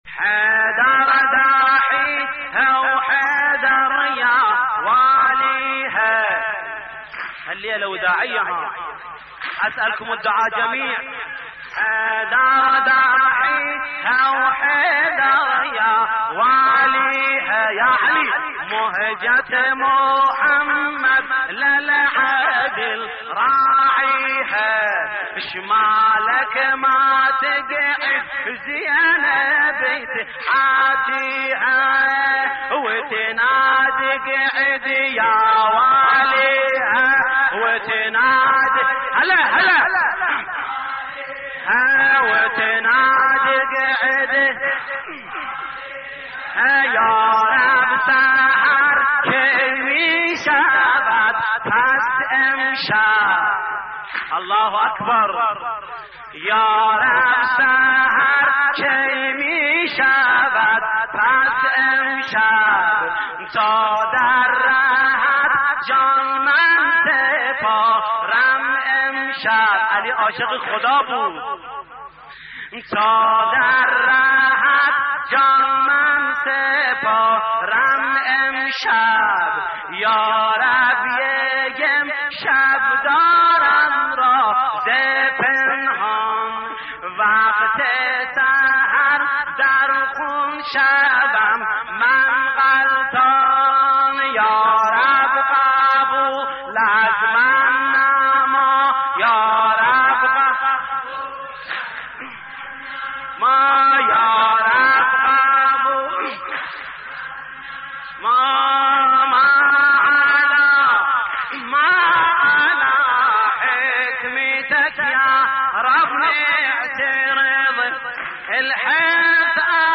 هوسات لحفظ الملف في مجلد خاص اضغط بالزر الأيمن هنا ثم اختر